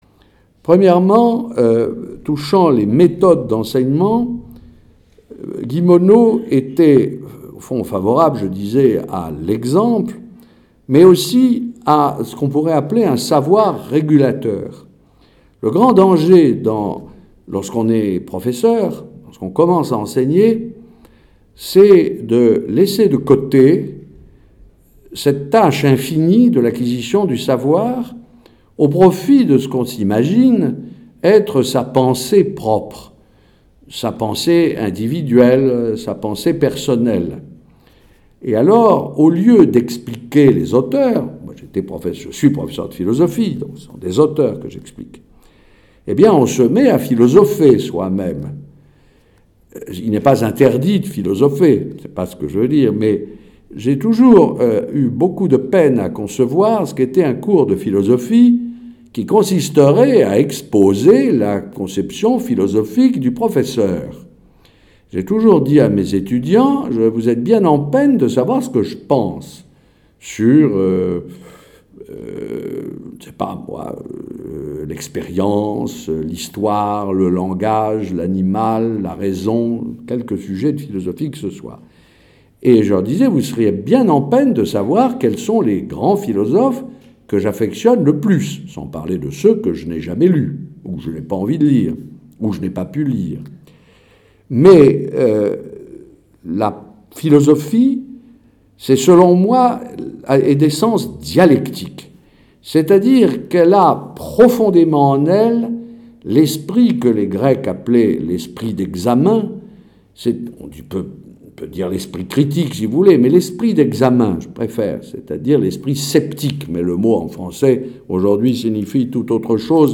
Journée Portes ouvertes à St Jean de Passy le 4 mars 2017
Conférence